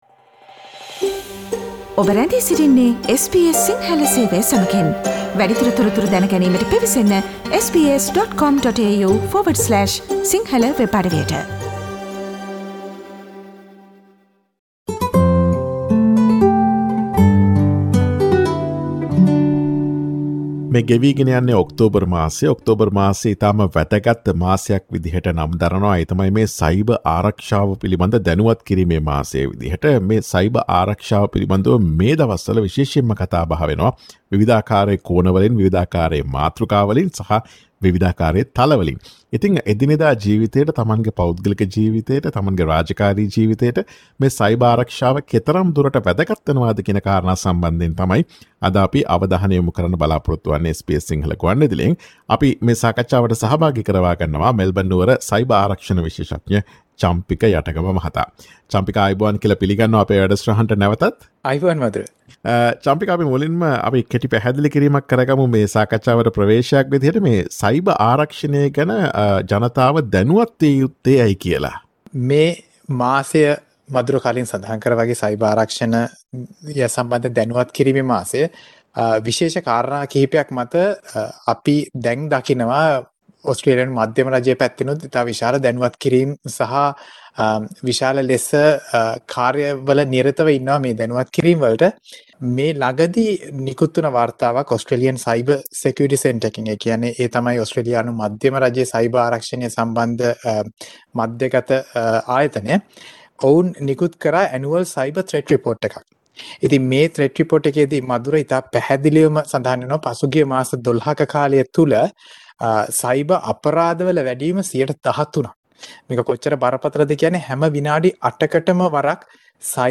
ඔක්තෝබර් මාසය සයිබර් ආරක්ෂණය පිළිබඳ දැනුවත් කිරීමේ මාසය වේ. එනිසාම සයිබර් අපරාධ සහ ඒවායෙන් මිදීමට ඔබට ගත හැකි පියවර SBS සිංහල ගුවන් විදුලිය සිදුකළ සාකච්ඡාවට සවන් දෙන්න.